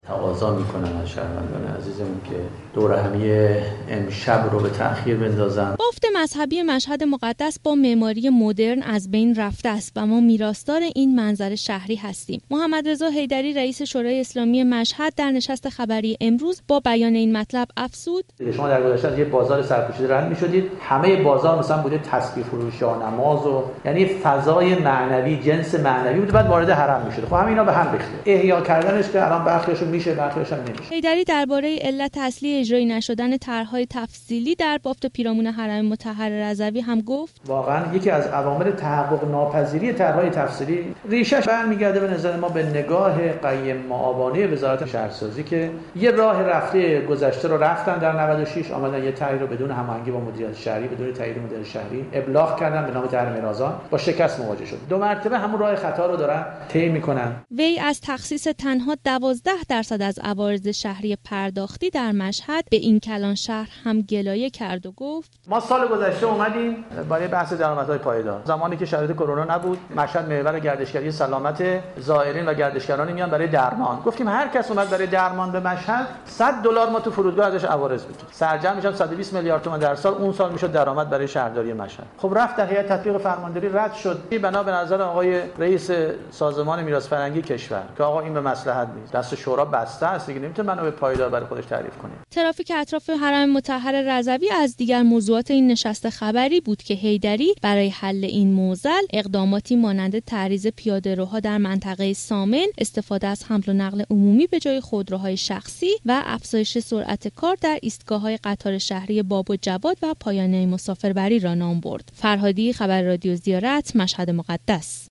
نشست خبری رئیس شورای اسلامی مشهد مقدس
خبرنگار رادیو زیارت از این نشست گزارش می دهد.